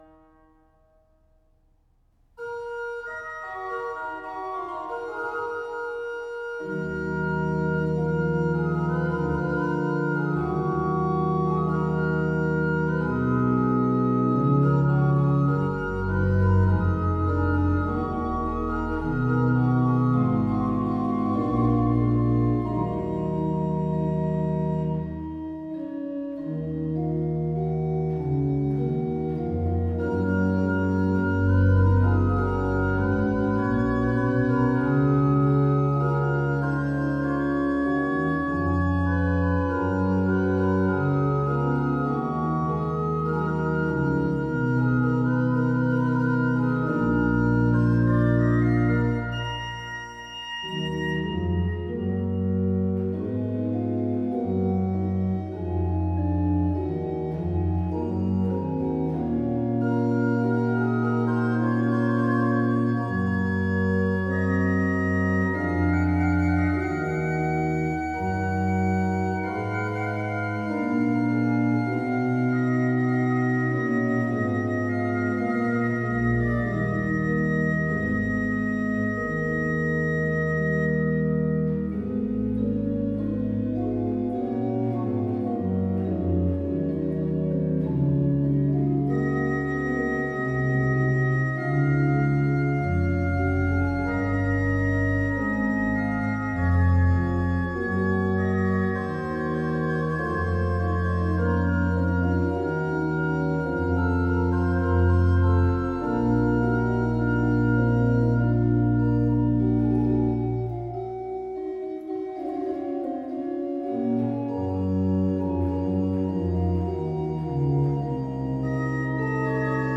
op het historische SCHNITGER orgel (1688) in Norden (D)
Werken voor orgel van Dietrich BUXTEHUDE (1637-1707)
Une prouesse à la fois technologique, artistique et acoustique : chacun des 5 plans sonores est distinctement audible partout dans l’église.